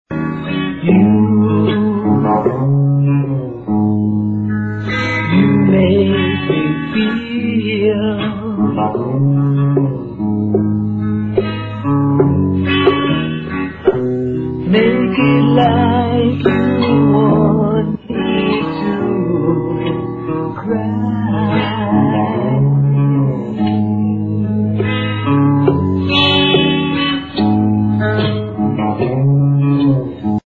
Home recordings 1970
drums, percussion
bass, vocals
guitar
lead vocals